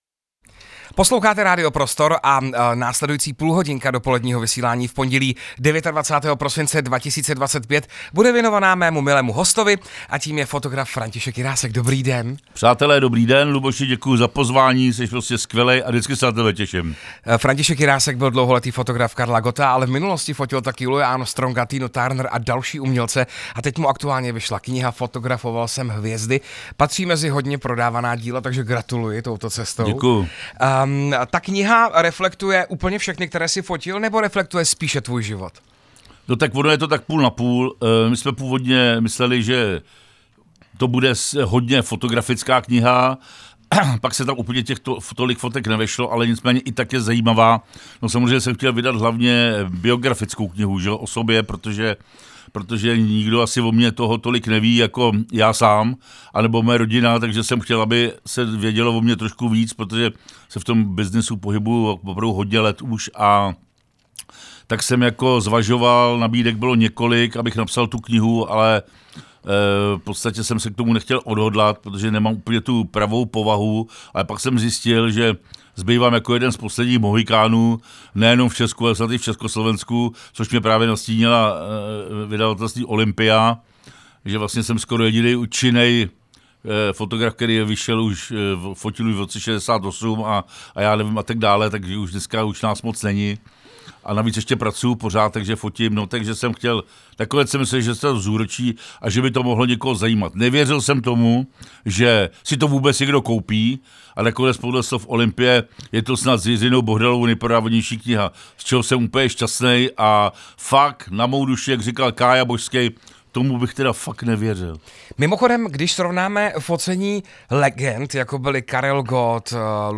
Nejen o tom jsme hovořili v živém vysílání | Radio Prostor